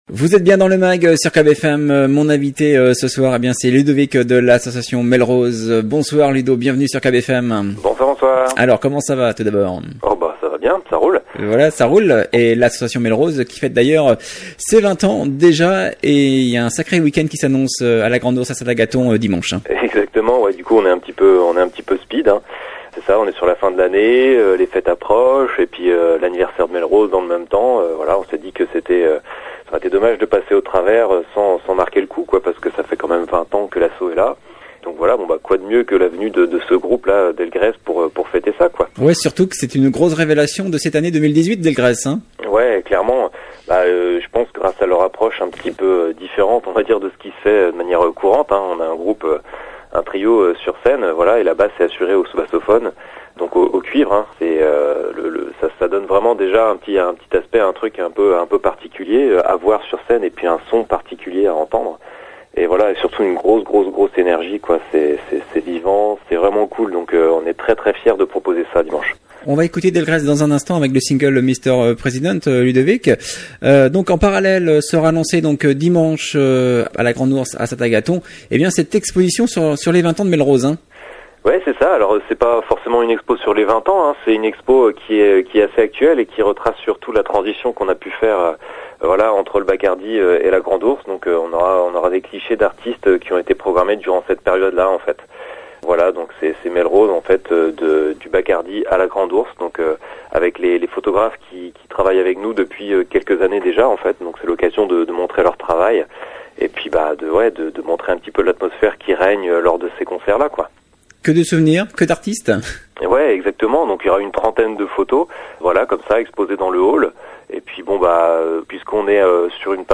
Invité du Mag hier soir